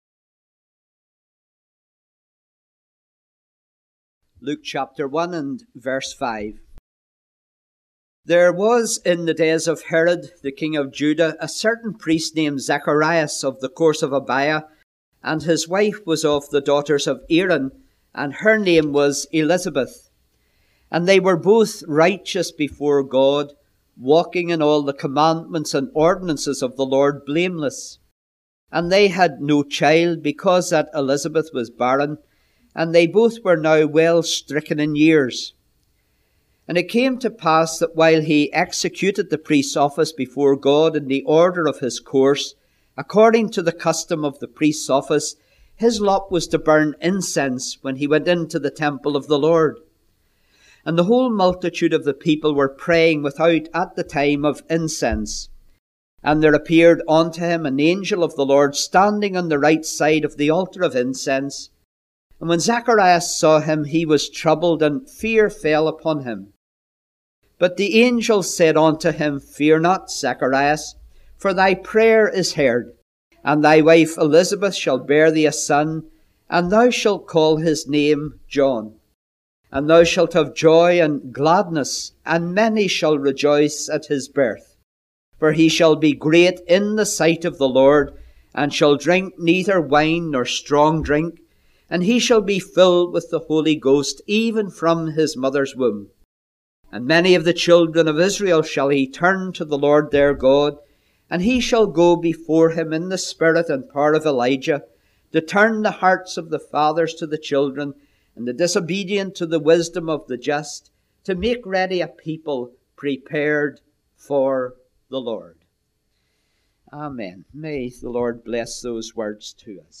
5 sermons